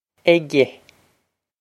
aige egg-eh
Pronunciation for how to say
This is an approximate phonetic pronunciation of the phrase.